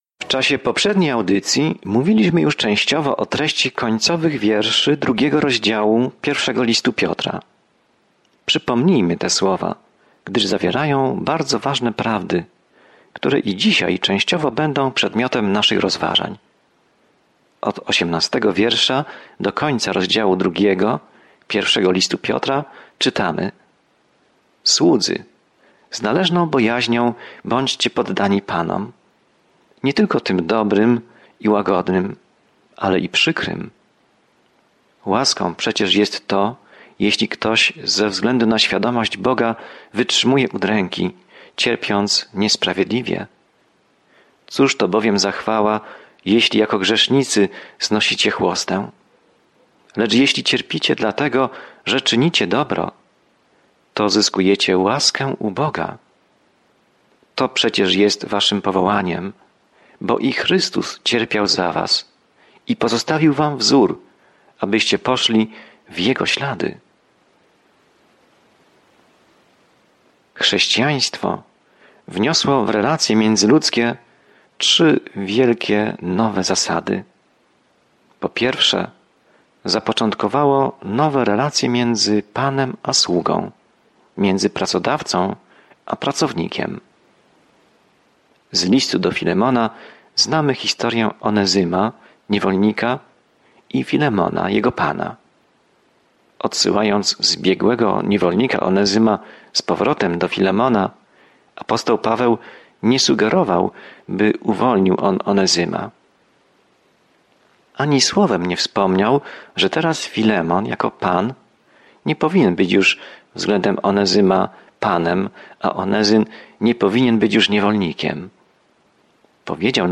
Pismo Święte 1 Piotra 2:22-25 1 Piotra 3:1-2 Dzień 8 Rozpocznij ten plan Dzień 10 O tym planie Jeśli cierpisz dla Jezusa, ten pierwszy list Piotra zachęca cię, abyś podążał śladami Jezusa, który pierwszy cierpiał za nas. Codziennie podróżuj przez I List Piotra, słuchając studium audio i czytając wybrane wersety ze słowa Bożego.